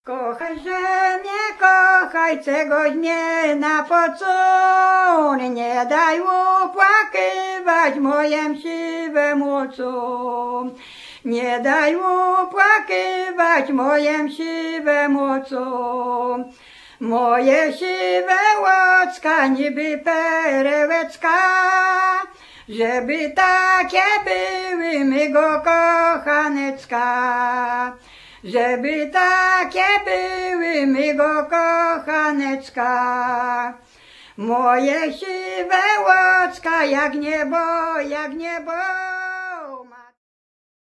Przy¶piewki polne Field ditties
The CD contains archival recordings made in 1993-2007 in the area of western Roztocze (Lubelskie region) and its surrounding villages.
¶piew vocals